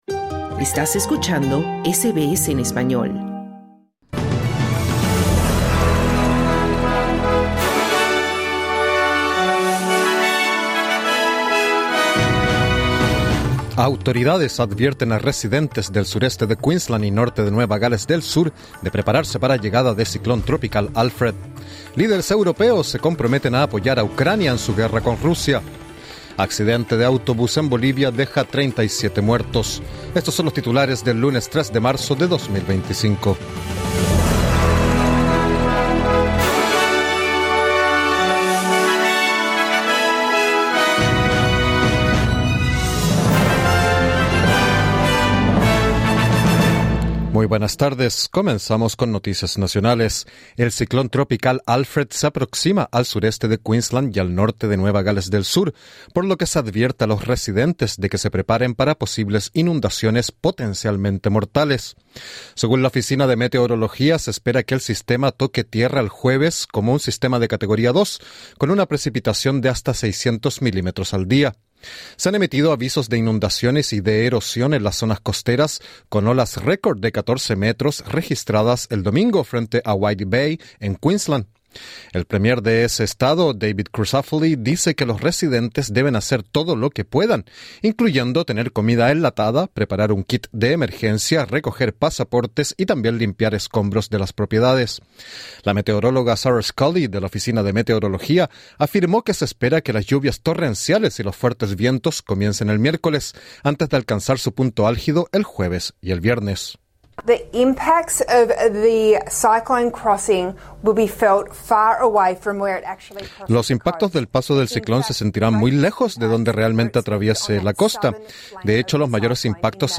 Líderes europeos se comprometen a apoyar a Ucrania en su guerra con Rusia. Accidente de autobús en Bolivia deja 37 muertos. Escucha el boletín en el podcast localizado en la parte superior de esta página.